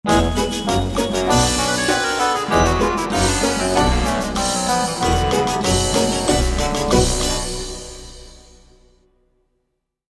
instrumental music cues can also be used to